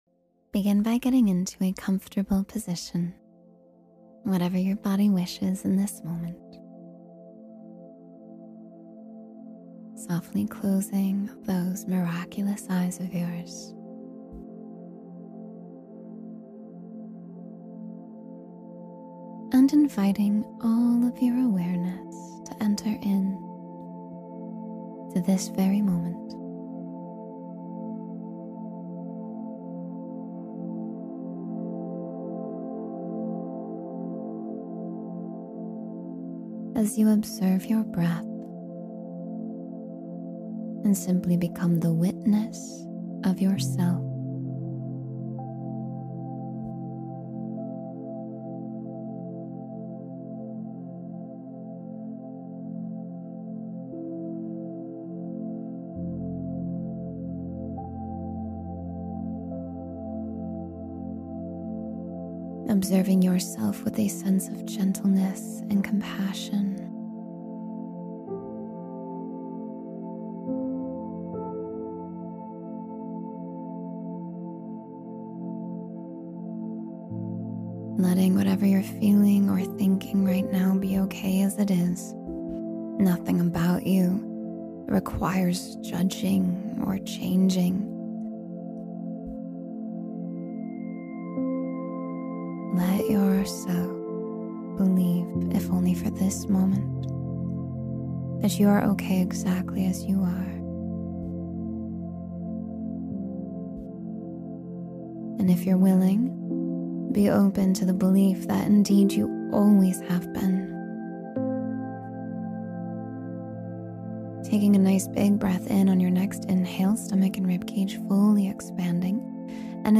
Radiate Confidence and Deep Self-Worth — Meditation for Empowerment